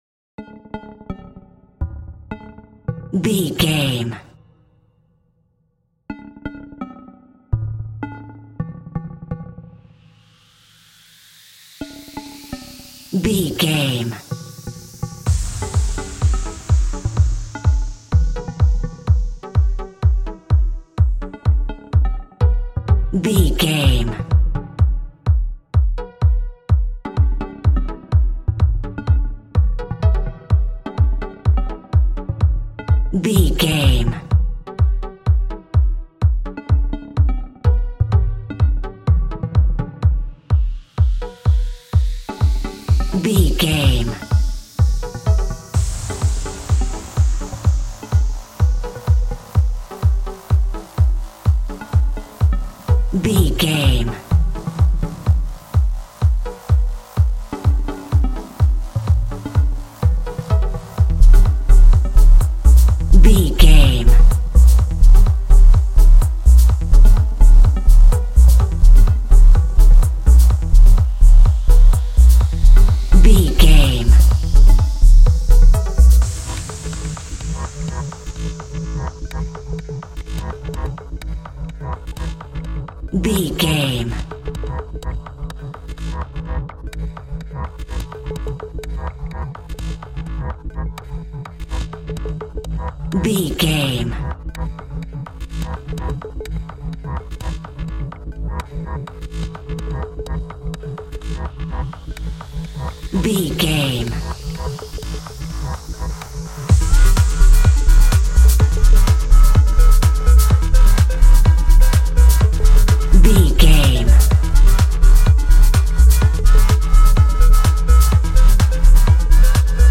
Top 40 Electronic Dance Music Alt.
Aeolian/Minor
G#
Fast
energetic
uplifting
futuristic
hypnotic
industrial
groovy
drum machine
synthesiser
house
techno
trance
instrumentals
synth leads
synth bass
upbeat